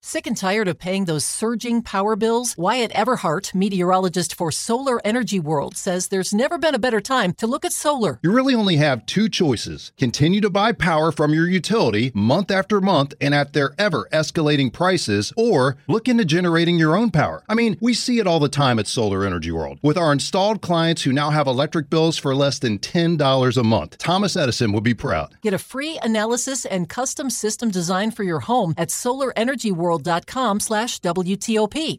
is answering some of the most pressing questions about shifting to solar power in 2025 in the new 5-part WTOP interview series below.